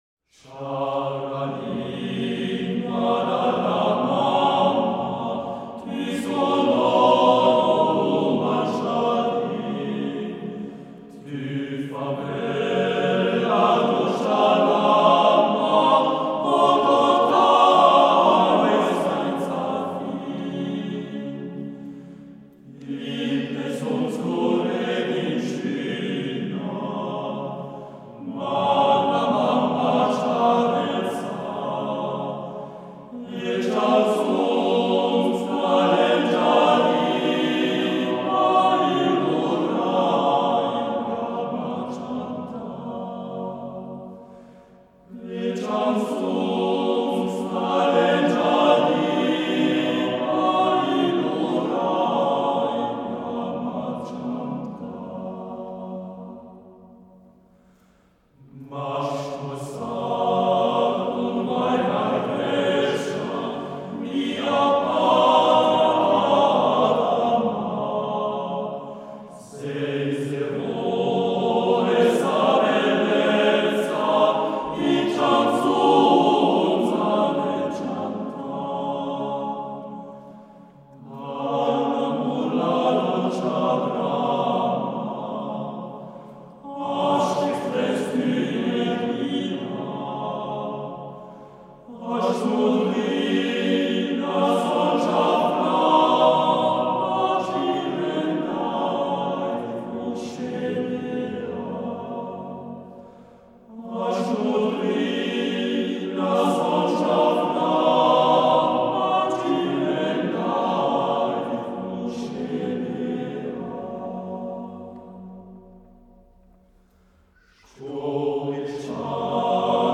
Zurich Boys’ Choir – The most beautiful Swiss folk songs and tunes (Vol. 2)